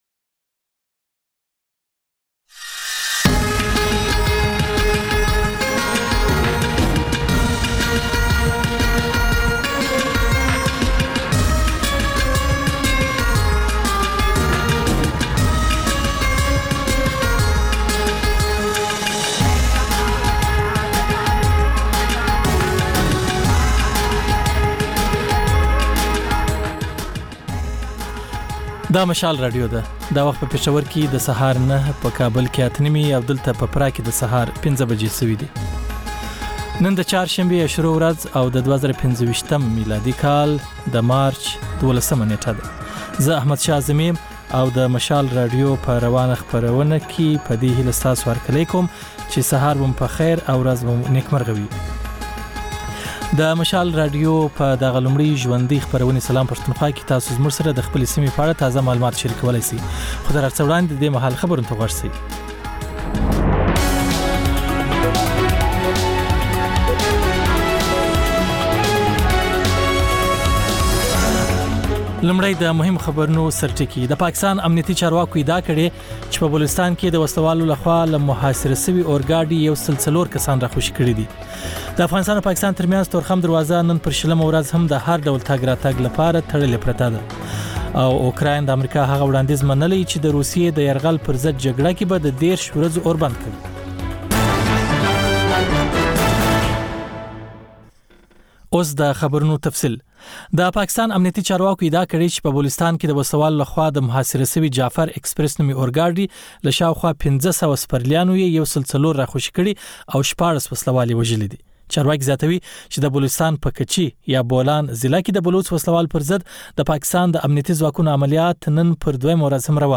دا د مشال راډیو لومړۍ خپرونه ده چې پکې تر خبرونو وروسته رپورټونه، له خبریالانو خبرونه او رپورټونه او سندرې در خپروو.